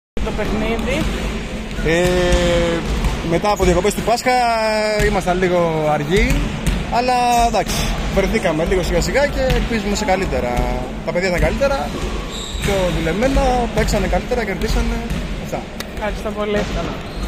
GAME INTERVIEWS: